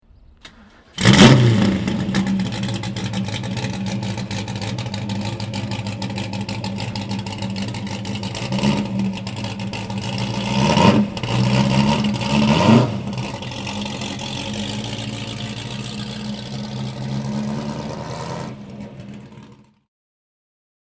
Motorsounds und Tonaufnahmen zu De Tomaso Fahrzeugen (zufällige Auswahl)
De Tomaso Pantera Gruppe 5 (1975) - Starten und Leerlauf
De_Tomaso_Pantera_Gruppe_5.mp3